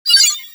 Teleporter.wav